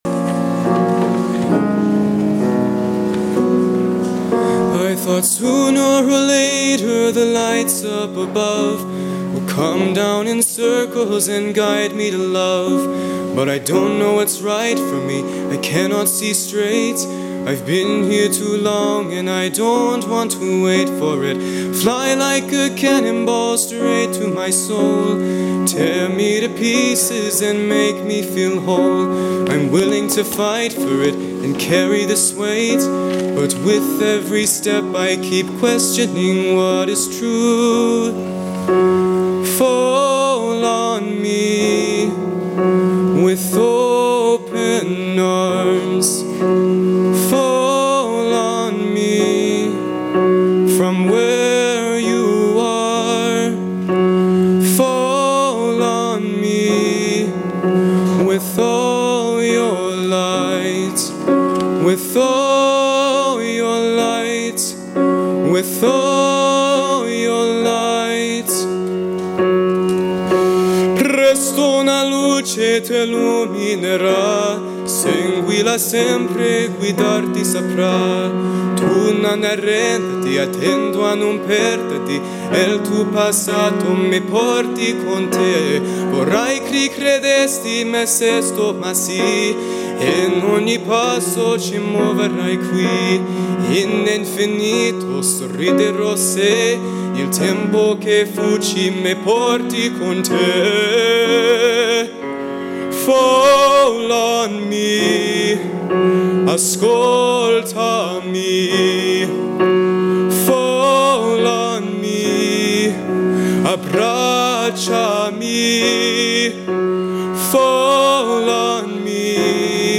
soloist